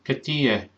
cateeya” (cad chuige = why). These ‘phonetic’ spellings give a better idea of the Donegal pronunciation than the standard spellings.